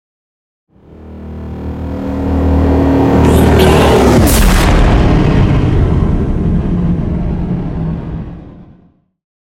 Dramatic electronic whoosh to hit trailer
Sound Effects
Atonal
futuristic
intense
woosh to hit